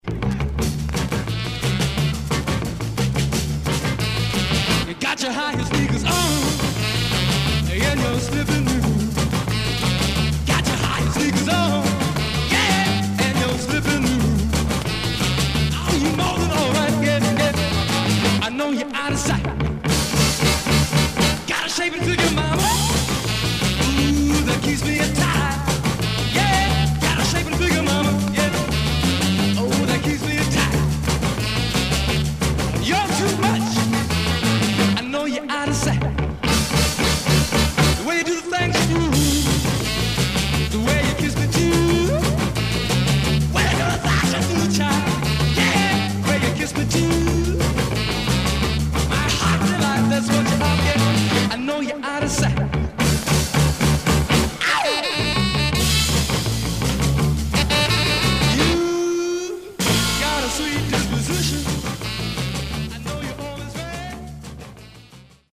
Some surface noise/wear
Mono
Rock